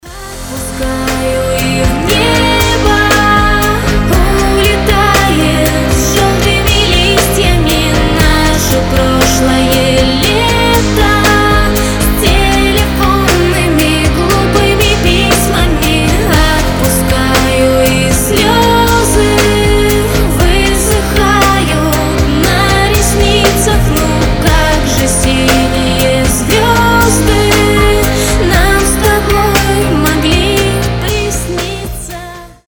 грустные
красивый женский голос